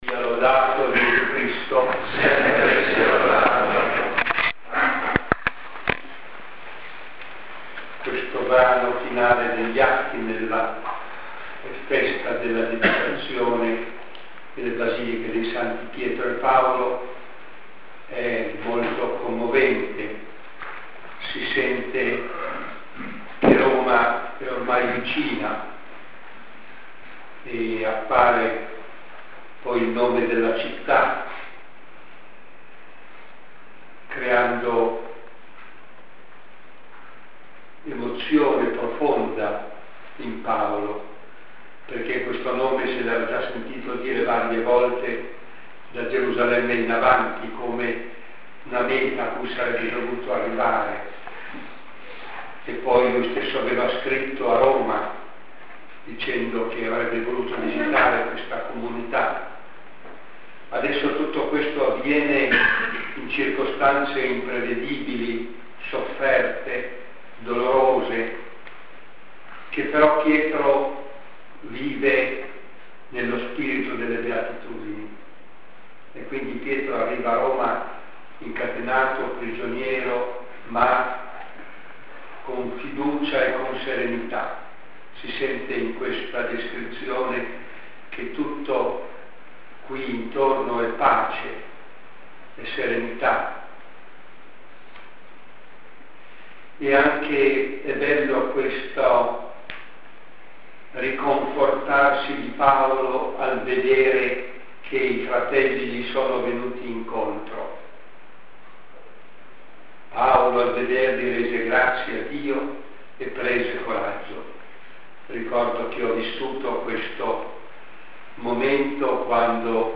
Esercizi spirituali al Clero Romano – Sacrofano (RM), 15-19 novembre 2004
Omelia 2 (Santa Messa ore 11.45 di giovedì 18 novembre)